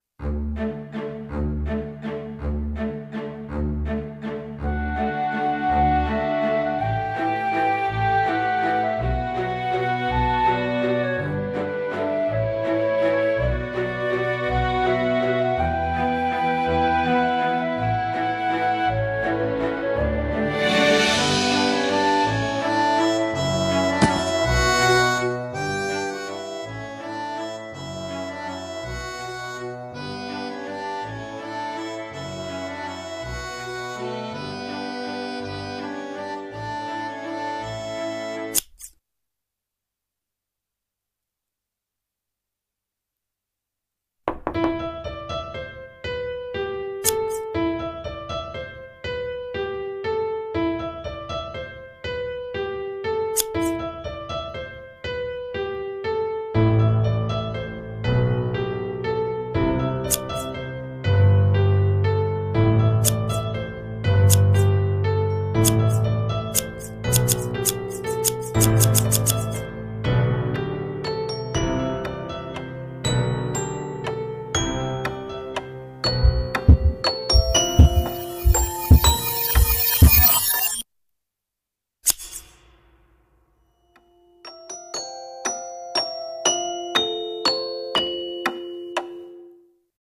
CM風声劇「鳥籠マリオネッタ」